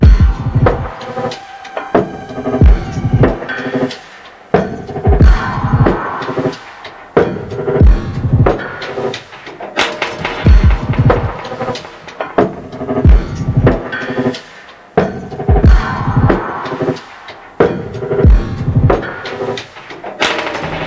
Toy Drums.wav